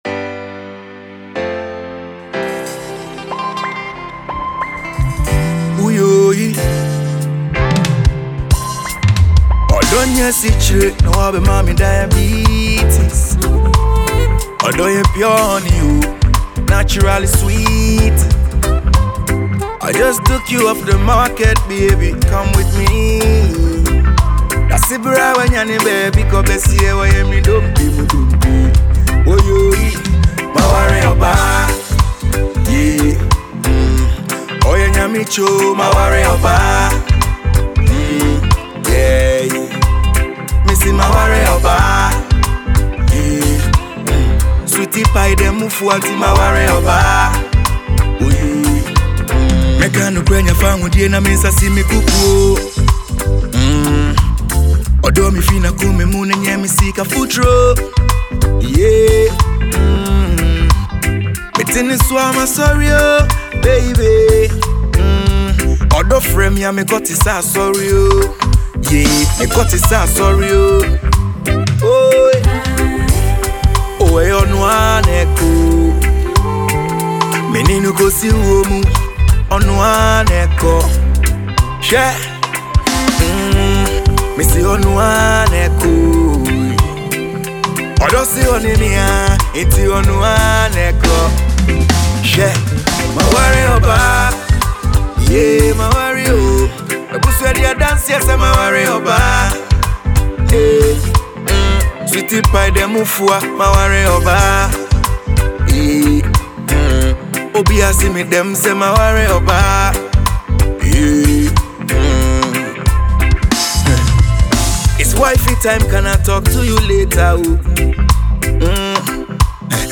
Reggae-themed love song